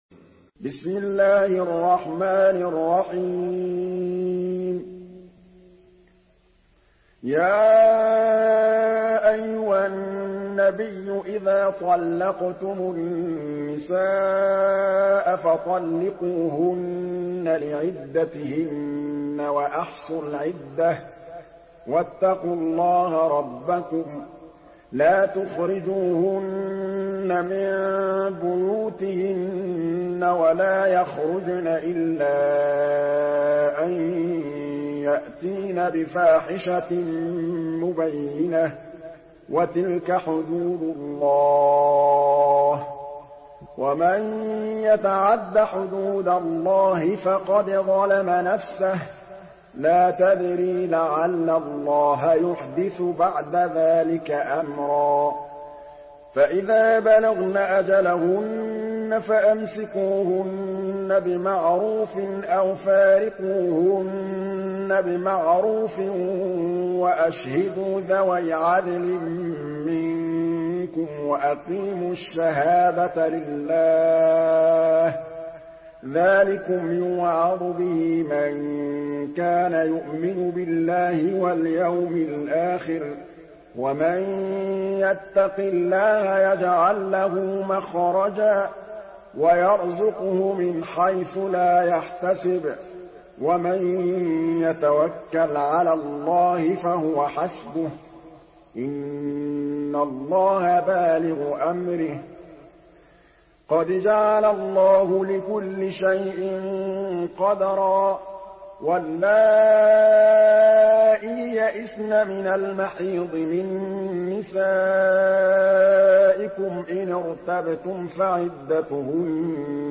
Surah At-Talaq MP3 by Muhammad Mahmood Al Tablawi in Hafs An Asim narration.
Murattal Hafs An Asim